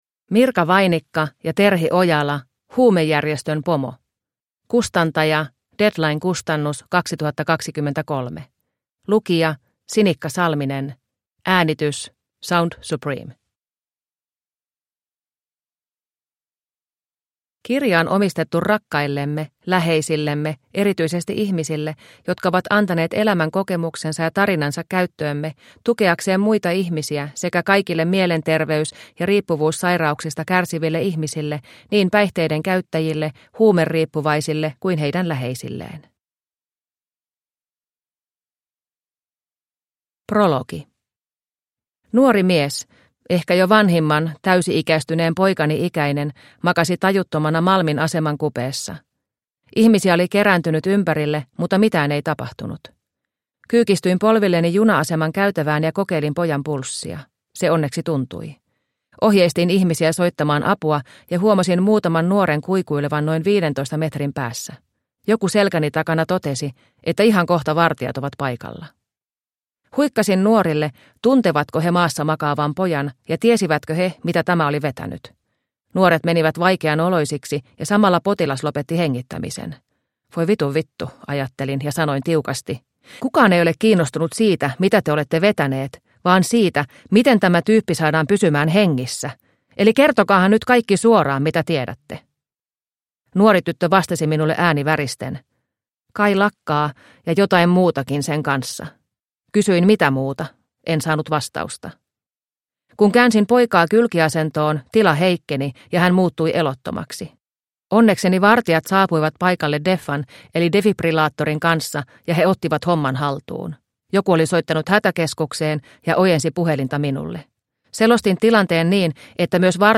Huumejärjestön pomo – Ljudbok – Laddas ner